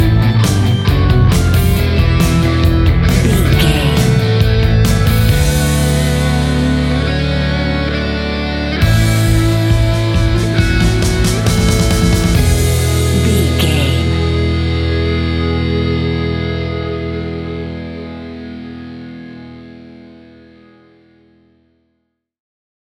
A great piece of royalty free music
Epic / Action
Fast paced
Ionian/Major
hard rock
distortion
punk metal
instrumentals
Rock Bass
Rock Drums
heavy drums
distorted guitars
hammond organ